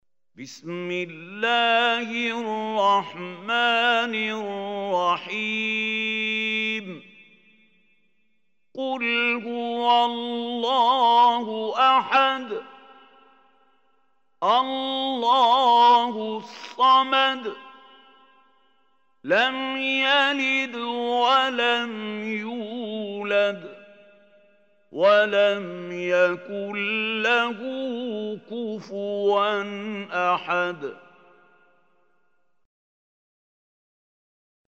Surah Ikhlas Recitation by Mahmoud Khalil Hussary
Surah Ikhlas is 112 surah of Holy Quran. Listen or play online mp3 tilawat / recitation in arabic in the beautiful voice of Sheikh Mahmoud Khalil Hussary.
112-surah-ikhlas.mp3